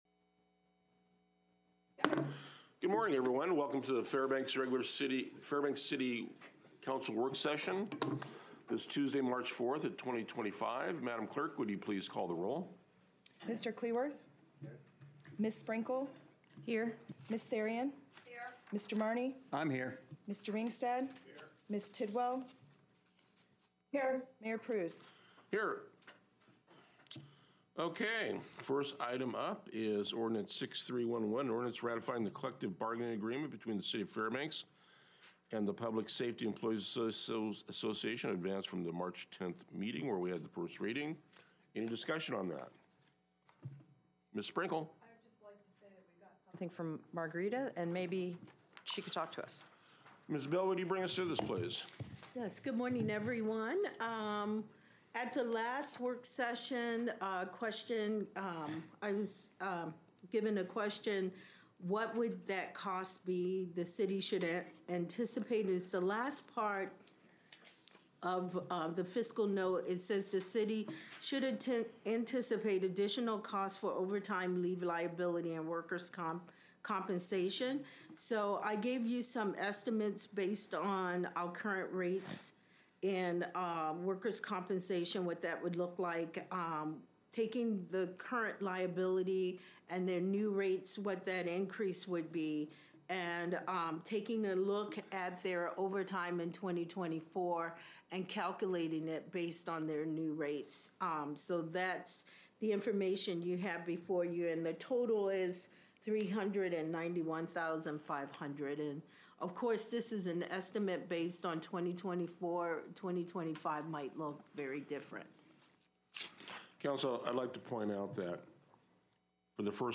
Regular City Council Work Session